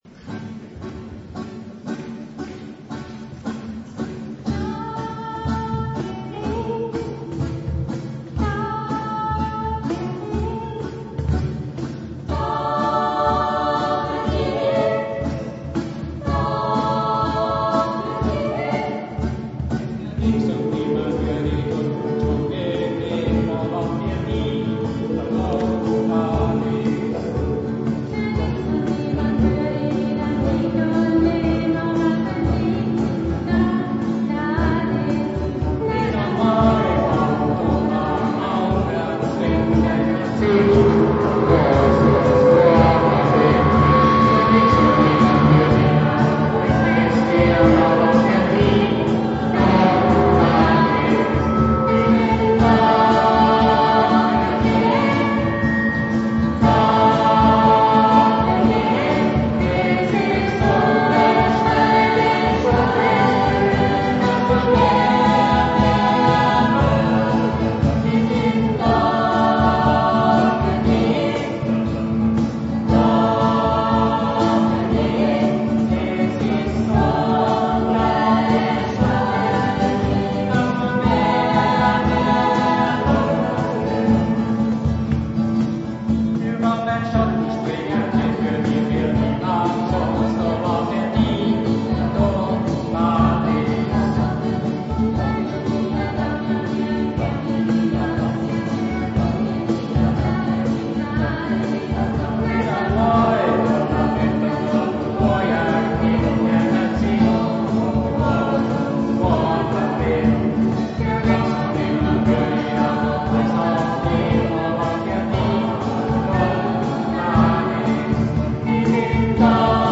Wie man auch an einem warmen Sommerabend die Kirche voll bekommt, bewies eindrucksvoll der Chor unserer Pfarre.
Alle Solisten/innen haben mit ihren Stimmen überzeugt, der Chor bewies sein Können in den harmonisch leisen Klängen genauso wie in den rhythmischen und temperamentvollen Liedern.